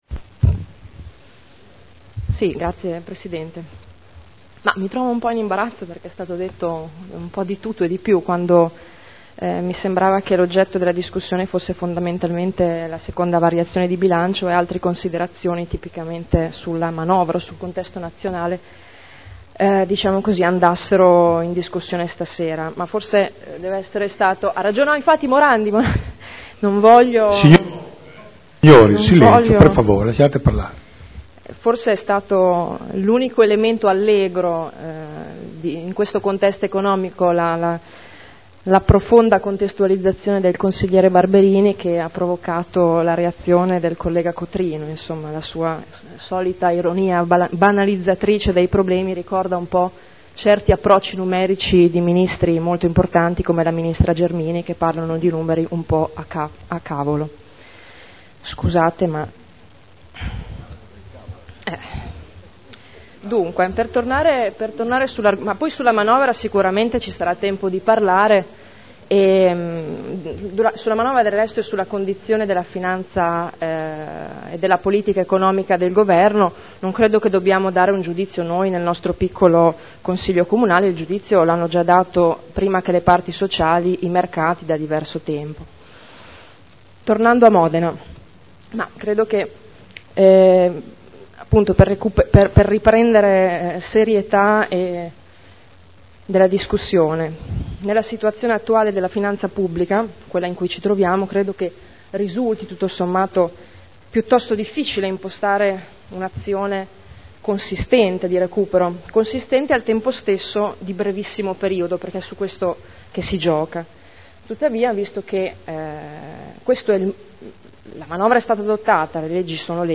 Giuliana Urbelli — Sito Audio Consiglio Comunale
Seduta del 26 settembre 2011 Bilancio di Previsione 2011 - Bilancio Pluriennale 2011-2013 - Programma triennale dei Lavori Pubblici 2011-2013 - Stato di attuazione dei programmi e verifica degli equilibri di bilancio - Variazione di Bilancio n. 2 Dibattito